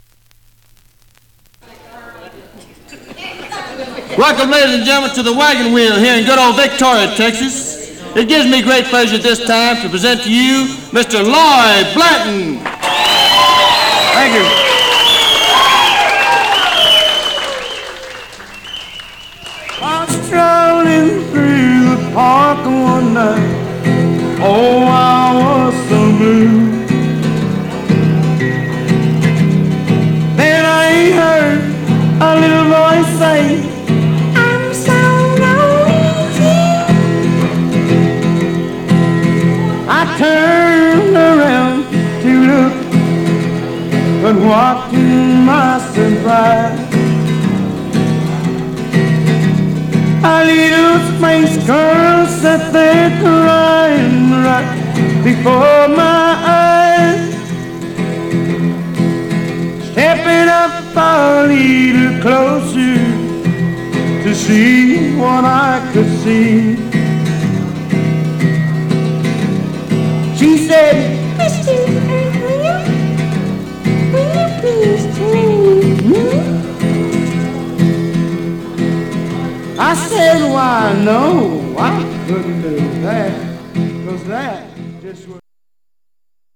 Some surface noise/wear
Mono
Country